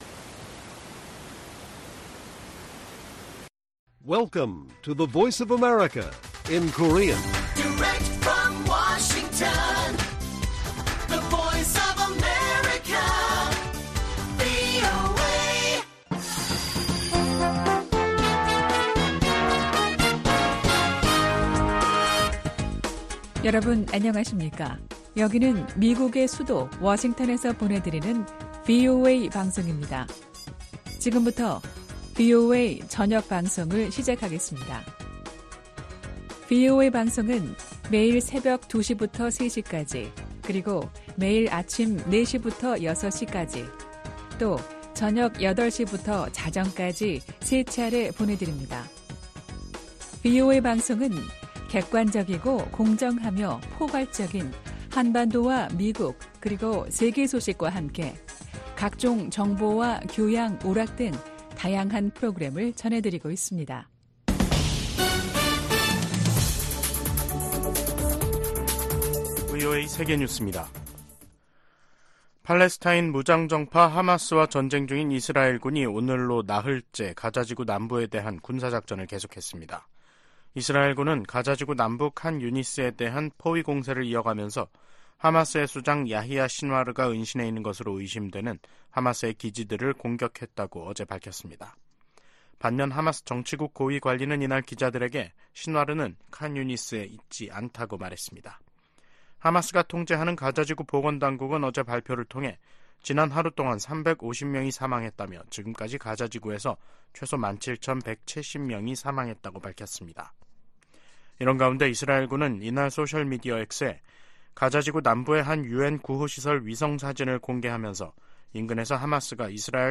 VOA 한국어 간판 뉴스 프로그램 '뉴스 투데이', 2023년 12월 8일 1부 방송입니다. 커트 캠벨 미 국무부 부장관 지명자가 상원 인준청문회에서 대북 억지력 강화의 필요성을 강조했습니다. 북한 정권의 불법 사이버 활동을 차단하기 위한 미국과 한국, 일본의 외교 실무그룹이 공식 출범했습니다. 미 상·하원이 9천억 달러에 달하는 내년 국방수권법 최종안을 공개했습니다.